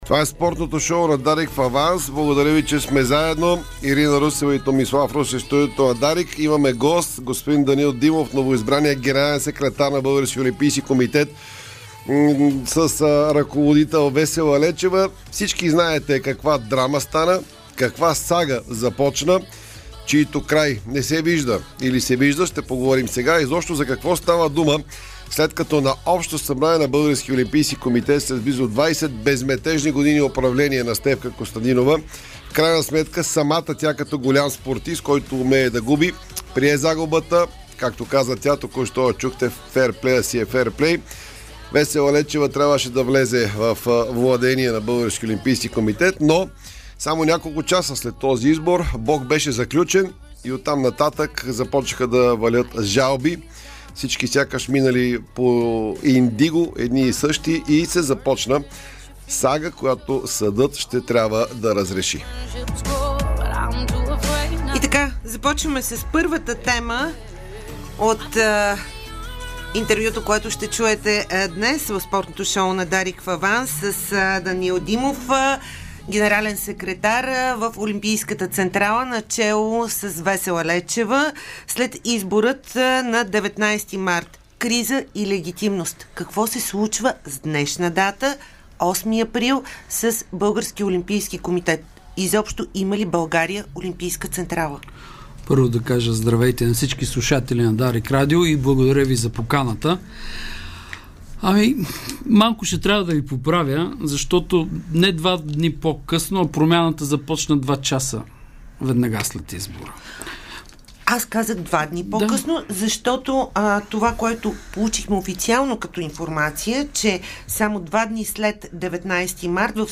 ексклузивно интервю пред Дарик радио